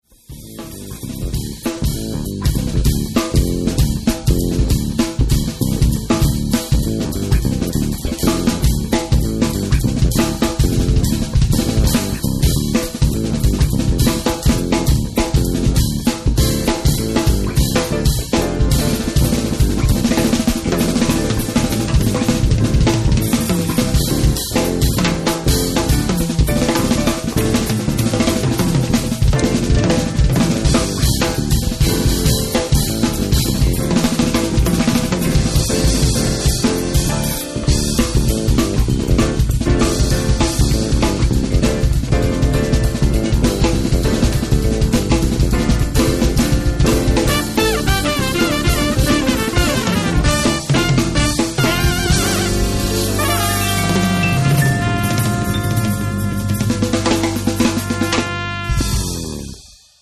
batteria